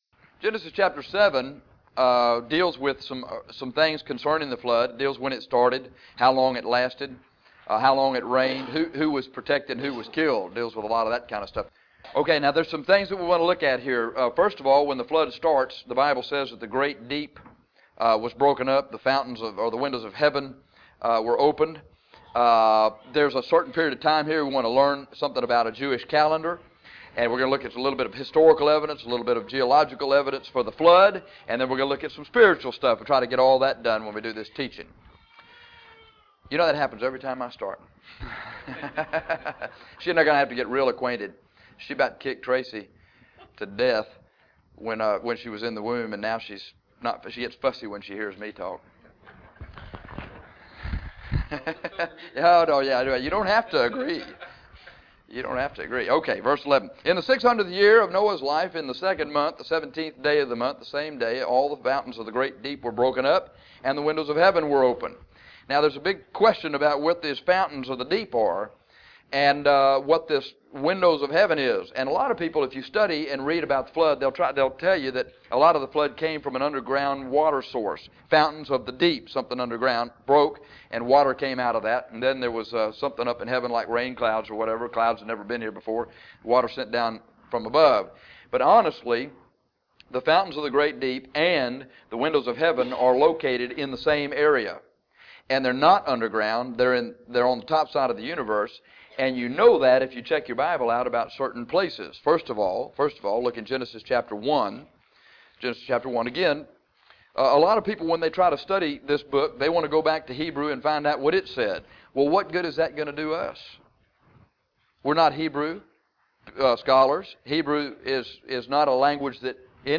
Genesis 7:11-24 Evidence for the Flood - Bible Believers Baptist Church | Corpus Christi, Texas